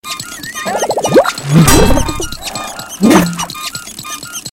tt_s_prp_sillyMeterPhaseTwo_mono.ogg